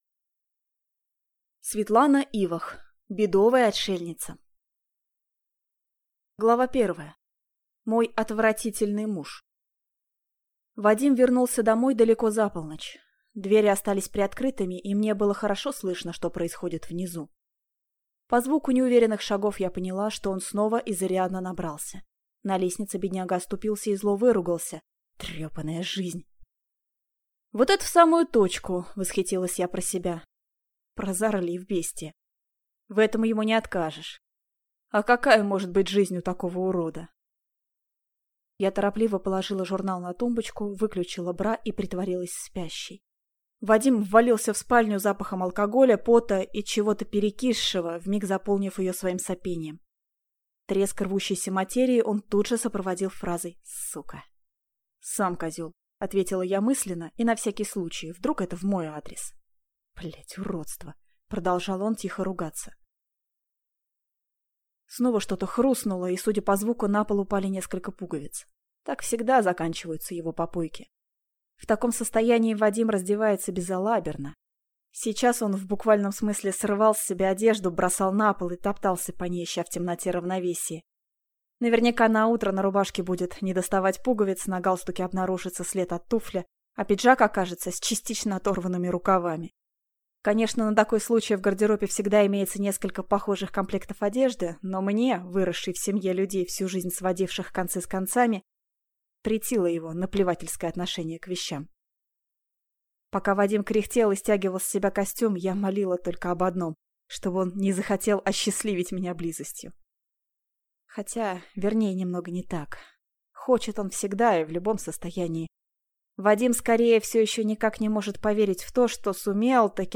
Аудиокнига Бедовая отшельница | Библиотека аудиокниг